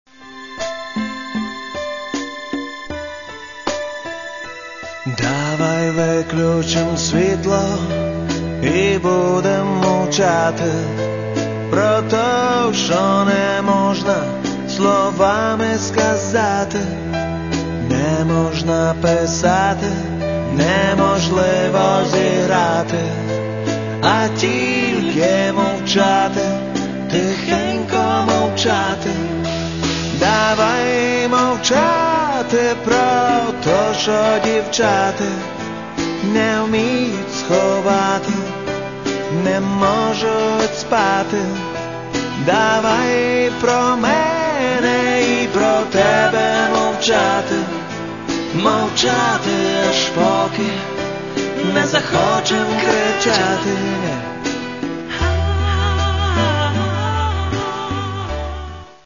Каталог -> Рок и альтернатива -> Электронная альтернатива